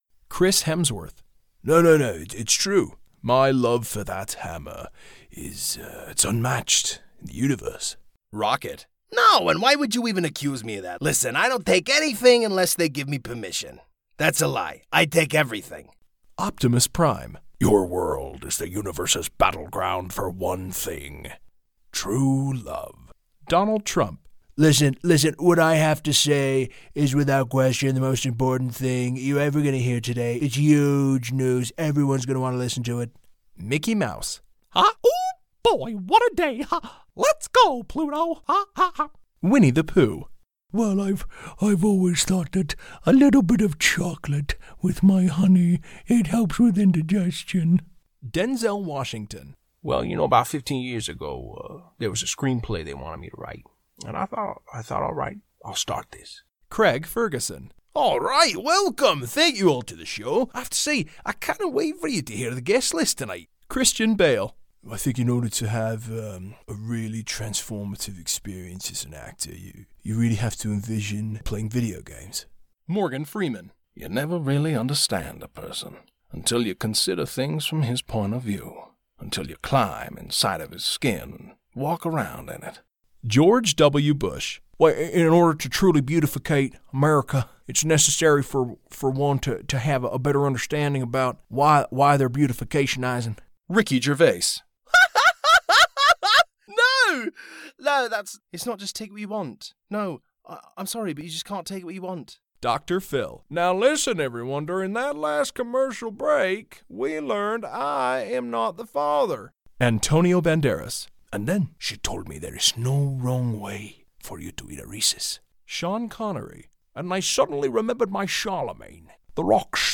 Celebrity Impressions↓ Download
Professionally-built, broadcast quality, double-walled LA Vocal Booth.